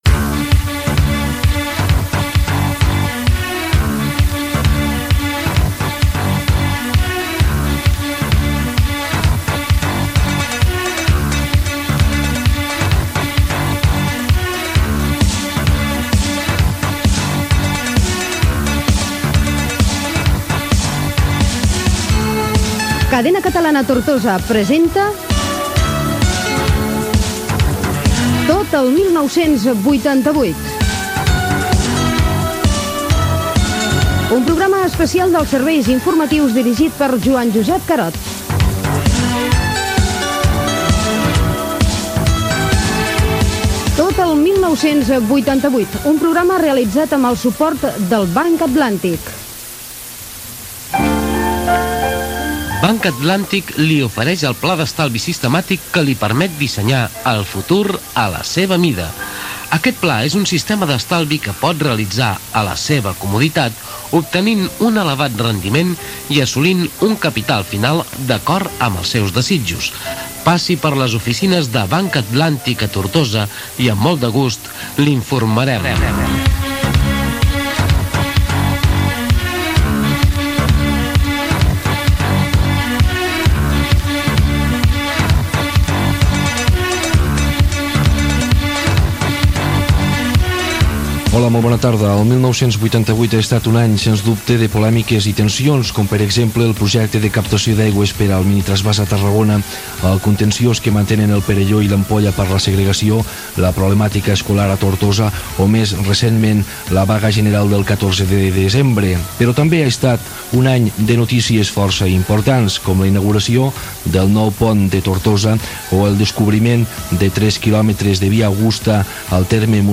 Careta del programa, publicitat i inici del resum informatiu de l'any
Informatiu
FM